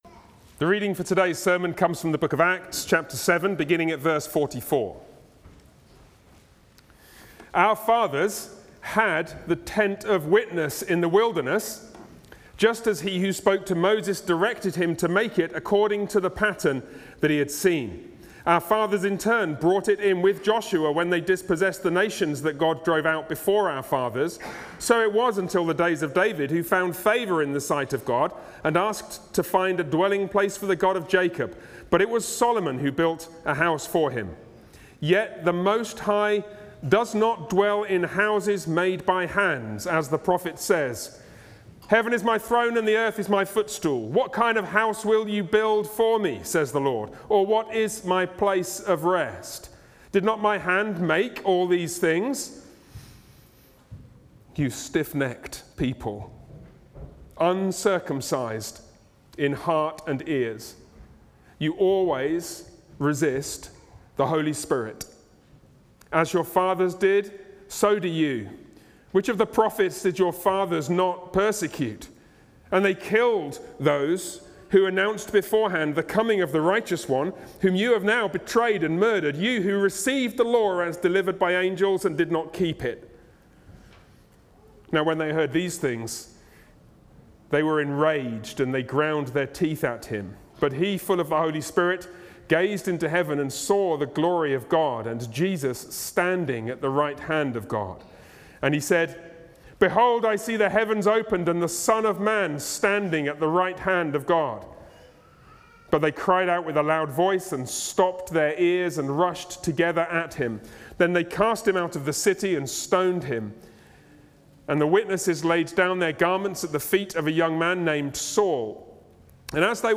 Passage: Acts 7:44-60 Service Type: Sunday worship